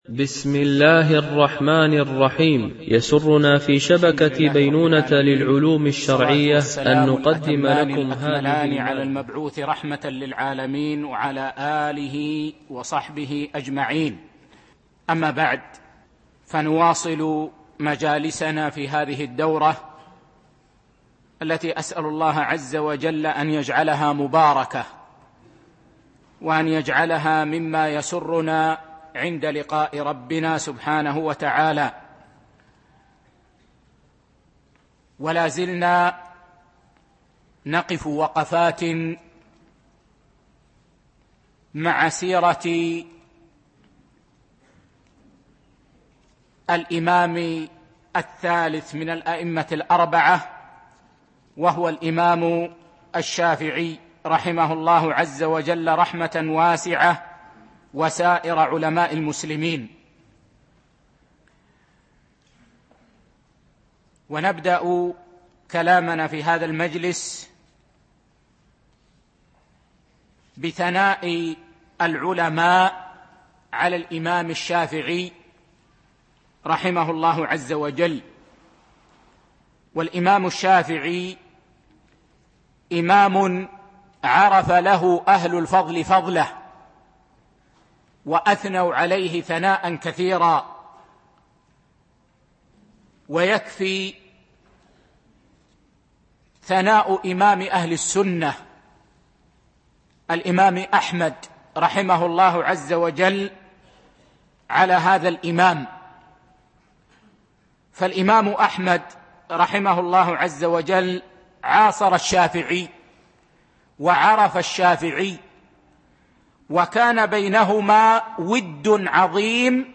الدرس
دبي